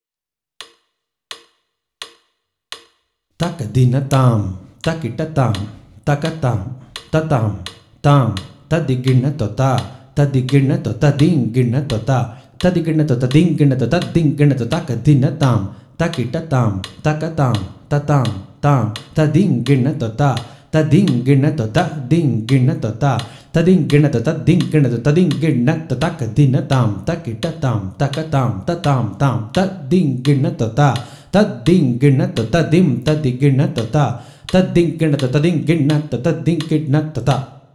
This is a set of 3 theermanams of 16 Beats each, and each theermanam is a combination of chaturashra nadai and trishra nadai.
Konnakol